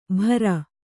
♪ bhara